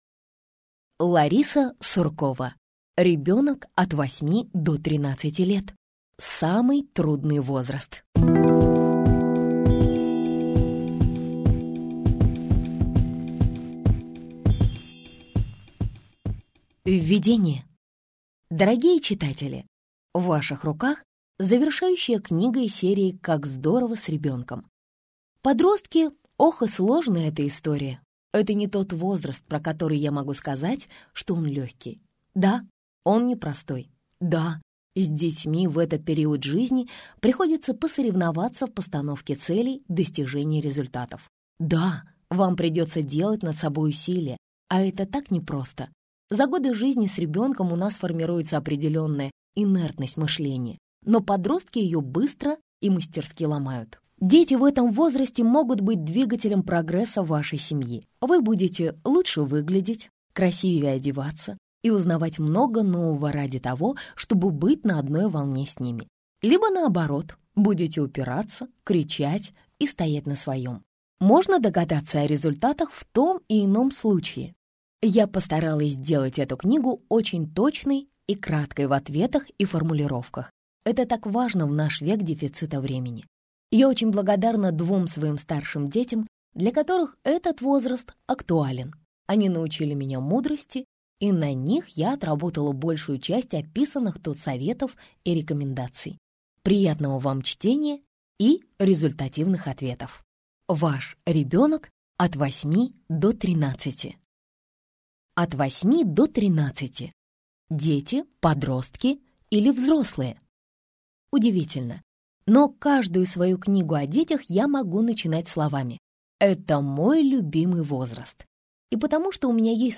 Аудиокнига Ребенок от 8 до 13 лет: самый трудный возраст | Библиотека аудиокниг